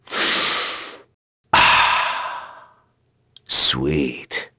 Crow-Vox_Skill7.wav